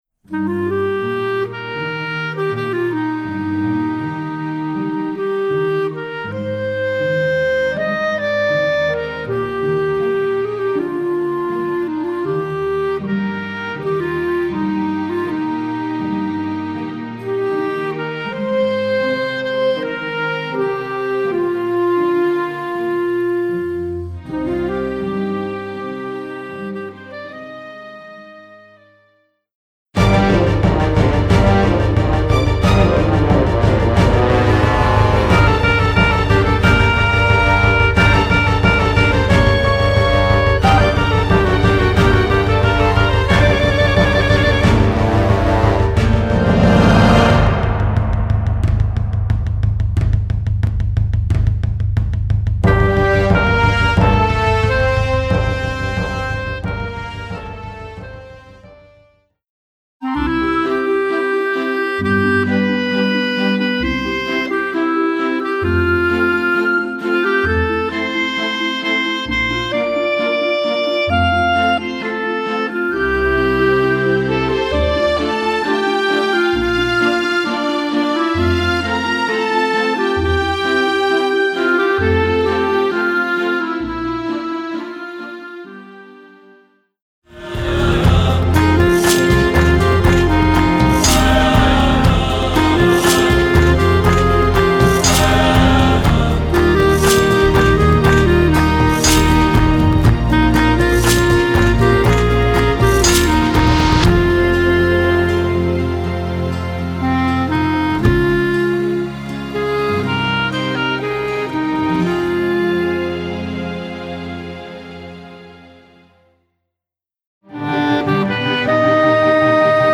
Voicing: Clarinet w/ Audio